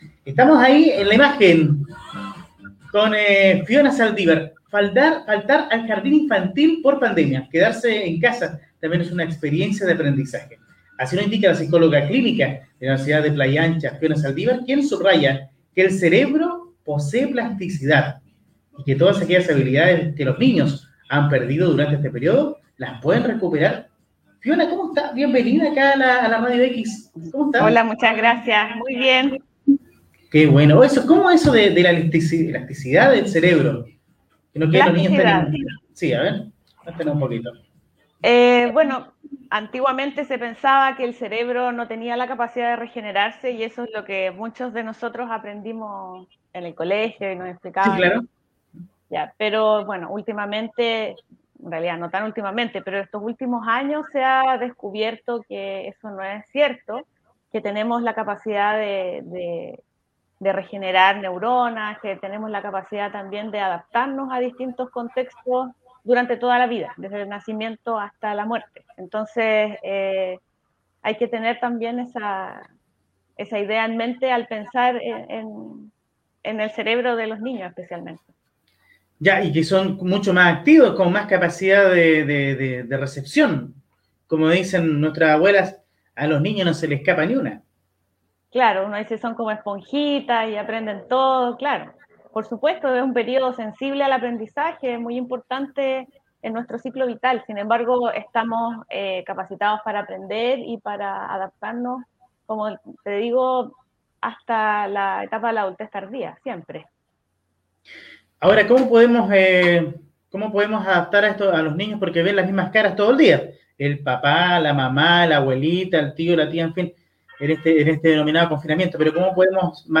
Como mencionaba el locutor, la entrevista radial se transmitió simultáneamente por Facebook, donde aún es posible revisar el video .